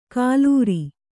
♪ kālūri